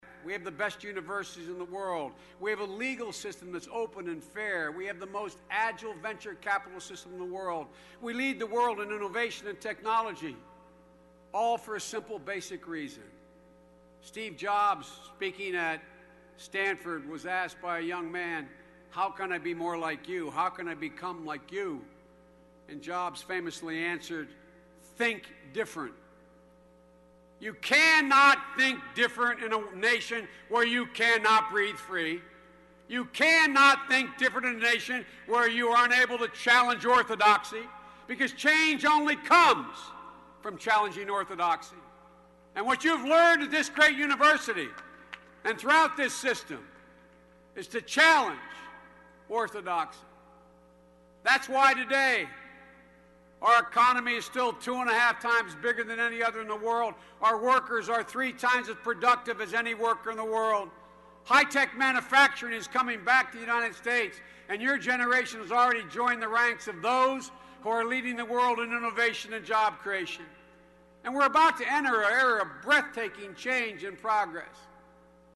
公众人物毕业演讲第411期:拜登2013宾夕法尼亚大学(9) 听力文件下载—在线英语听力室